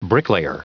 Prononciation du mot bricklayer en anglais (fichier audio)
Prononciation du mot : bricklayer